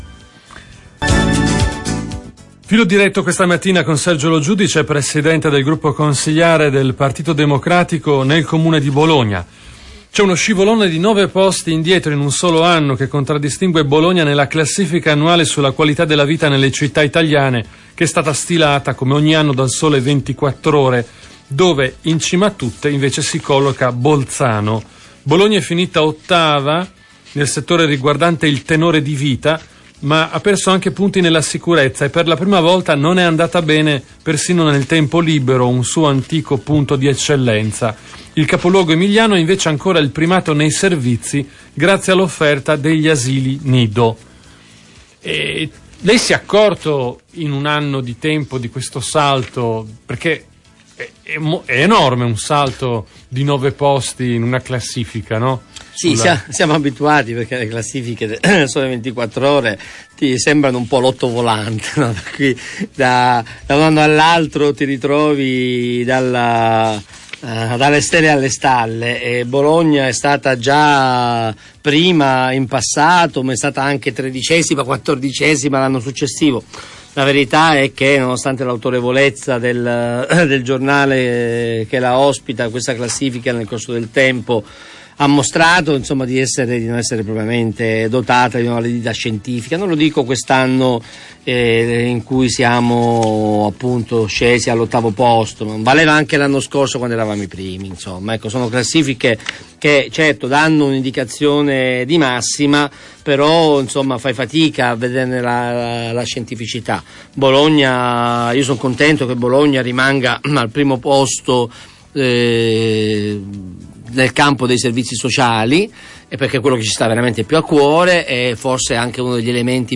L'indagine del Sole 24Ore che retrocede Bologna per qualità della vita e consumi ma la salva per il buon livello dei servizi: da questa sponda parte l'intervista a tutto campo del capogruppo Pd Sergio Lo Giudice a Radio Tau.